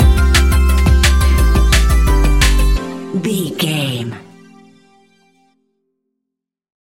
Ionian/Major
electronic
dance
techno
trance
synths
synthwave